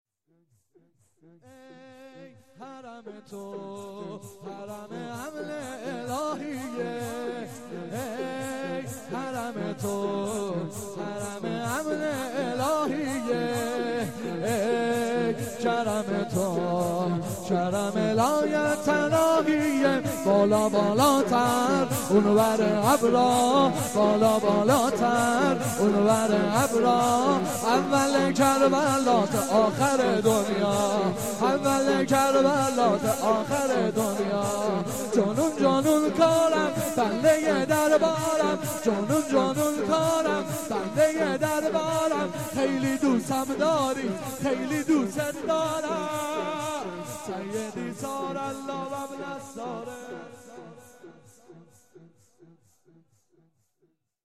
شور - ای حرم تو
روضه هفتگی